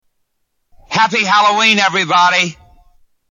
Category: Comedians   Right: Personal